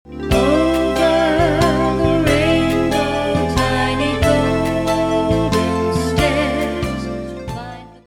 PERSONALIZED LULLABY